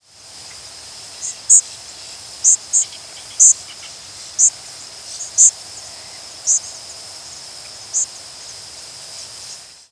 Indigo Bunting diurnal flight calls
Two Birds in flight with Boat-tailed Grackle and Yellow-rumped Warbler calling in the background.